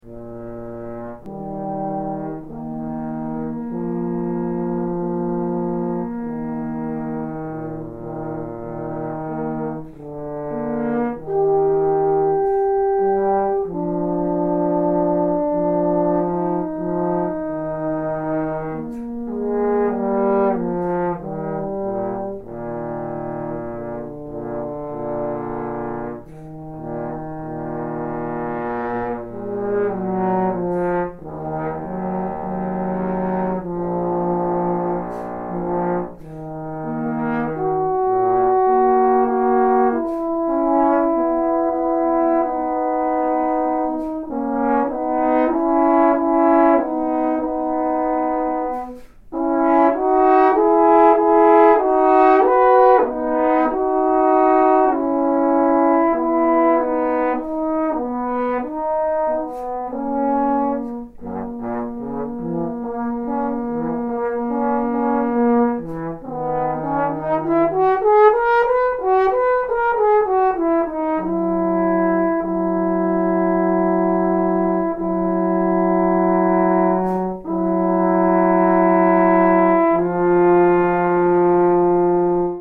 In other words, I recorded both duet parts separately, and put them together using Audacity, a free, open-source audio editing tool. The first excerpt I tried was the 4th horn solo from Beethoven’s 9th Symphony.
This is a “raw” recording, with little to no editing, other than what was needed to align both parts. The recorder was placed on my music stand, approximately 2-3 feet away.
There are a few places where the lower voice pokes out too much, but overall it isn’t too bad.
If nothing else, the intonation discrepancies in the recording can serve as an example of how this duet might be used in a lesson situation.